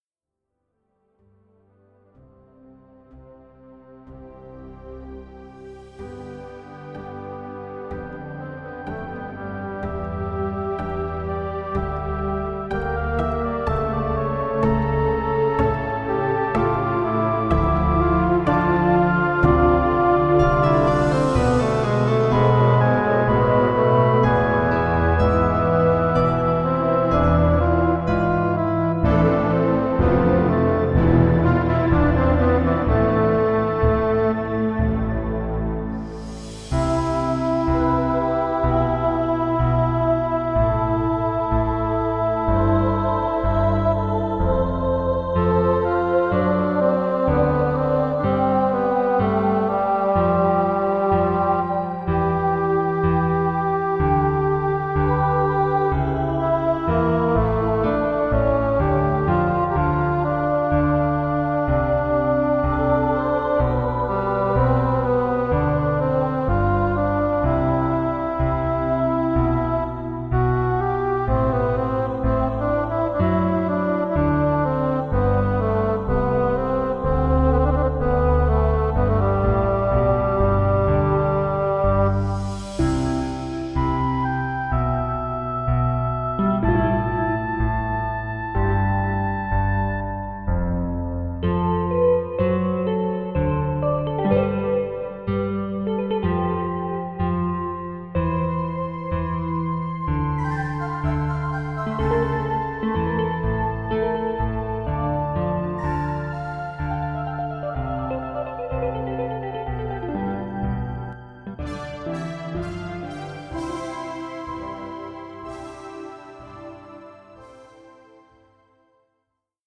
pure classical gold reimagined for your listening pleasure